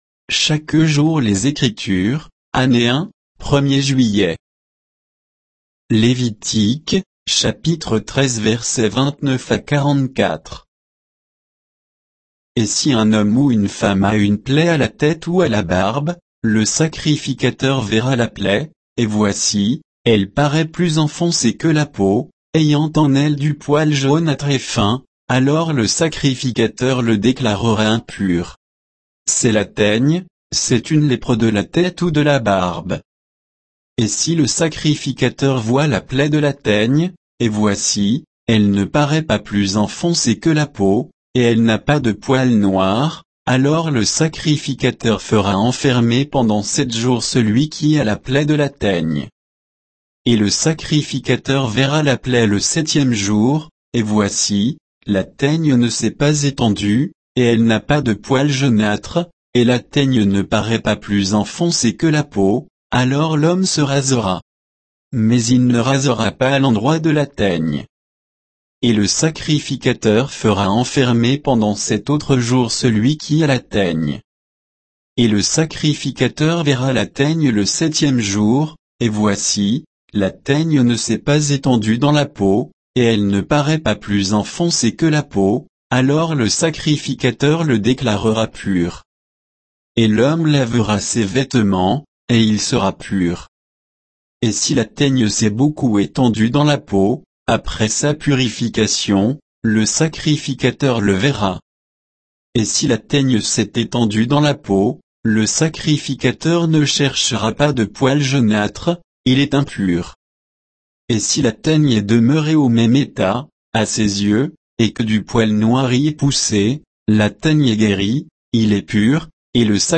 Méditation quoditienne de Chaque jour les Écritures sur Lévitique 13, 29 à 44